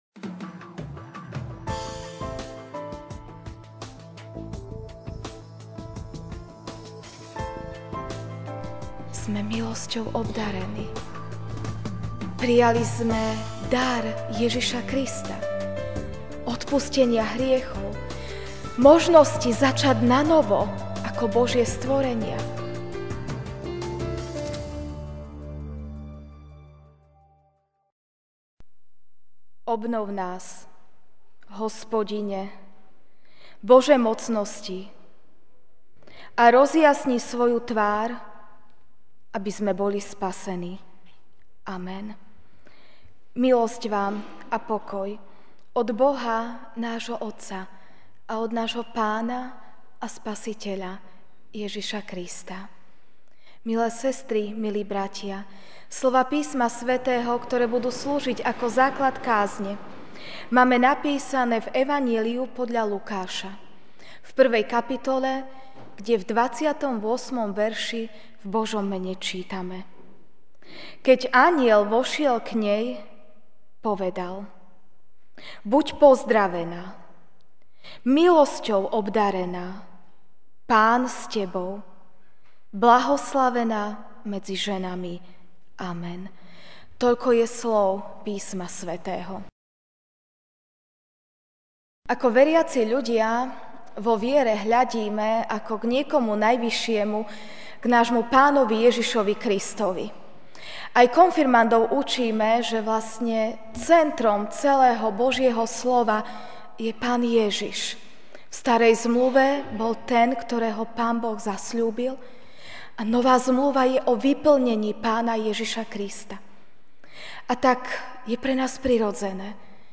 mar 26, 2017 Milosťou obdarená MP3 SUBSCRIBE on iTunes(Podcast) Notes Sermons in this Series Ranná kázeň: Milosťou obdarená (Lukáš 1, 28) Keď anjel vošiel k nej, povedal: Buď pozdravená, milosťou obdarená, Pán s tebou!